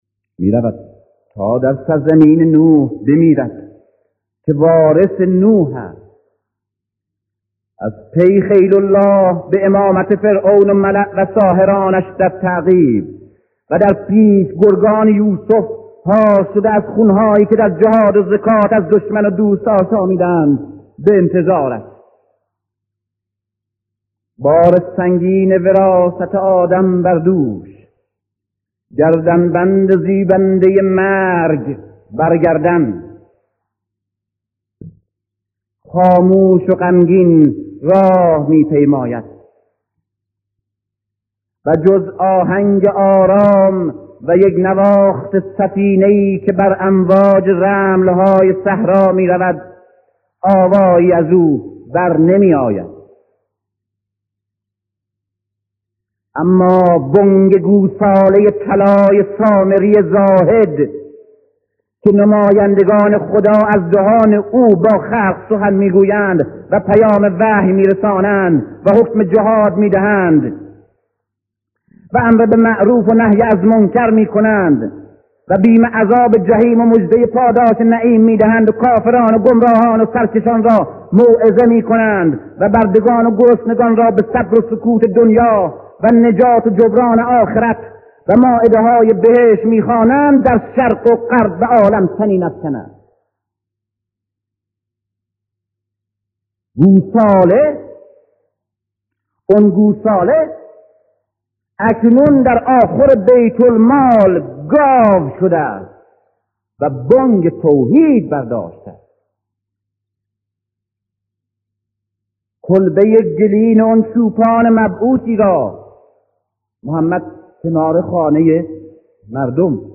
کتاب صوتی حسین وارث آدم ـ دکتر علی شریعتی